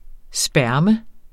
Udtale [ ˈsbæɐ̯ˀmə ]